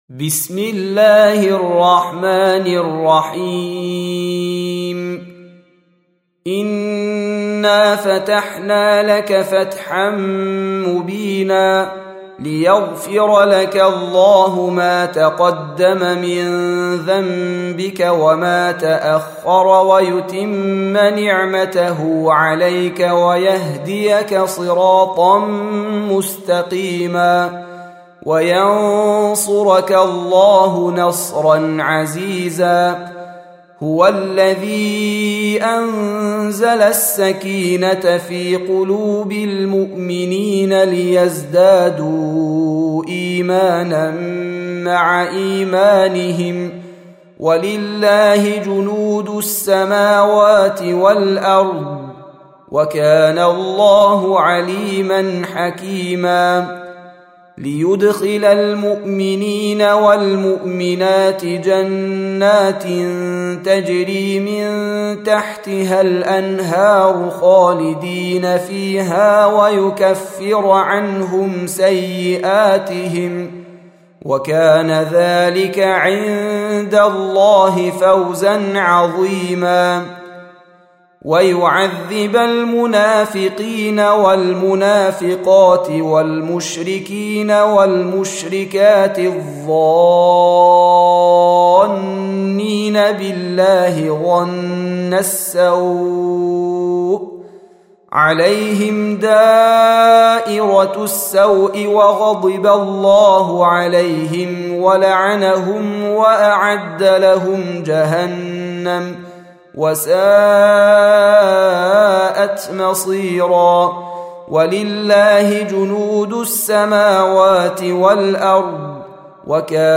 48. Surah Al-Fath سورة الفتح Audio Quran Tarteel Recitation
Surah Repeating تكرار السورة Download Surah حمّل السورة Reciting Murattalah Audio for 48. Surah Al-Fath سورة الفتح N.B *Surah Includes Al-Basmalah Reciters Sequents تتابع التلاوات Reciters Repeats تكرار التلاوات